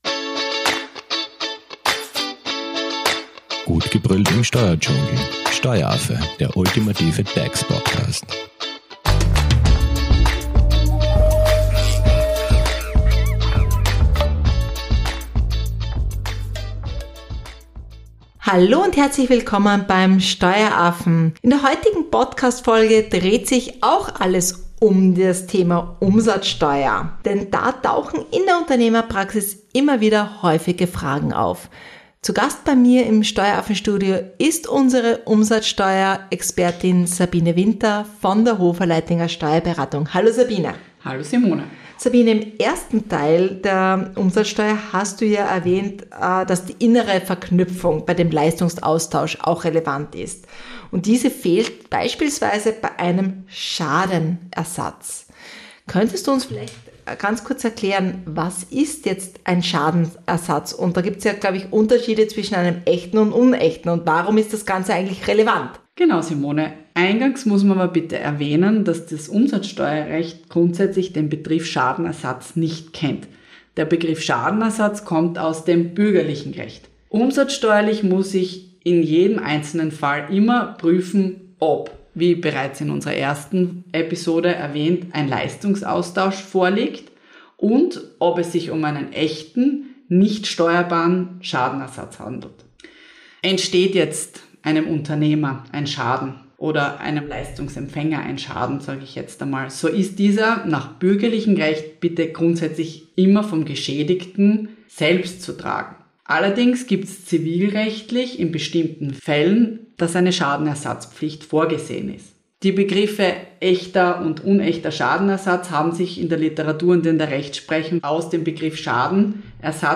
Mit anschaulichen Praxisbeispielen erklären wir, welche Tatbestände relevant sind, wer die Steuer schuldet und worauf man unbedingt achten sollte, um böse Überraschungen zu vermeiden. Unser Studio-Gast